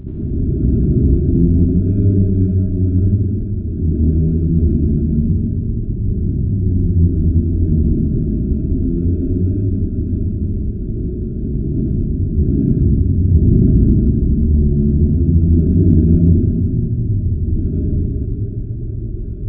Infrasound
arenaltremor_100t_rev.wav